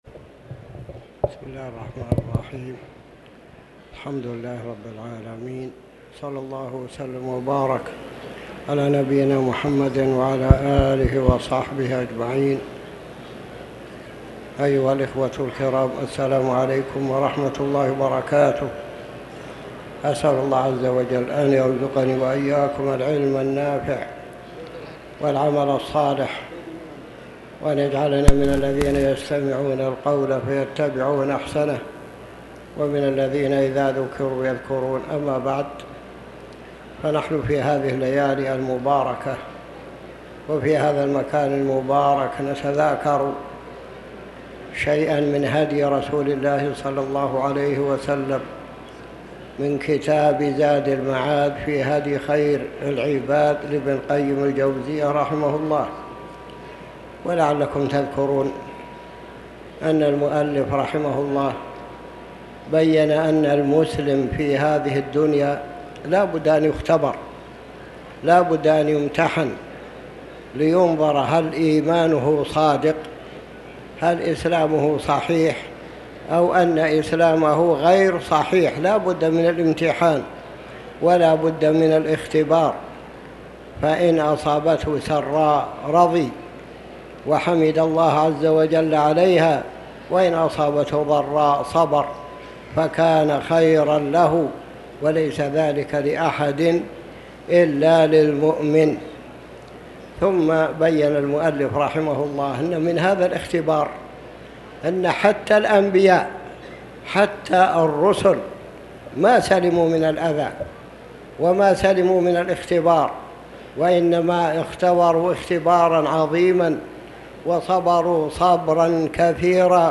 تاريخ النشر ٢٤ ذو الحجة ١٤٤٠ هـ المكان: المسجد الحرام الشيخ